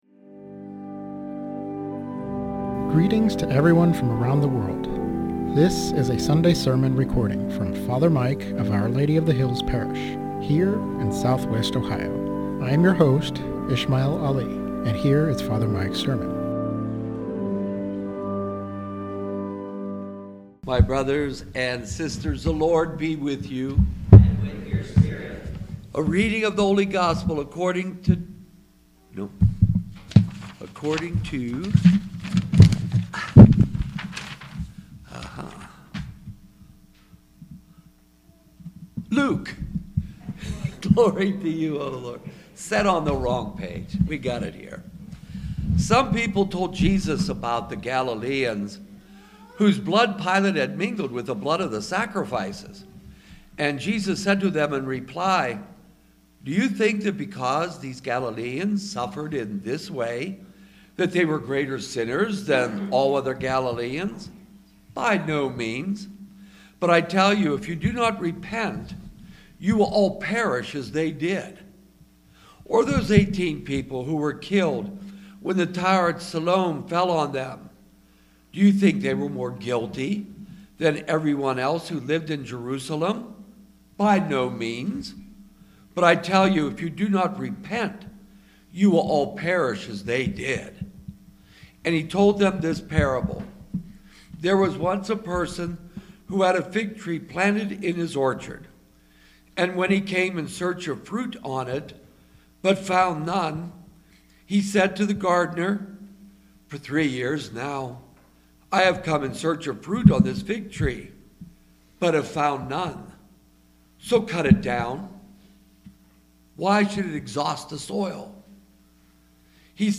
Sermon on Luke 13:1-9 - Our Lady of the Hills - Church